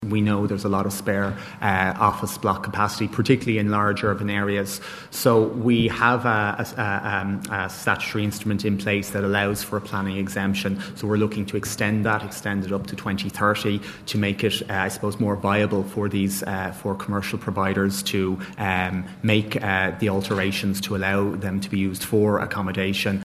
Integration Minister Roderic O’Gorman says commercial office blocks would also be used as a contingency measure………………..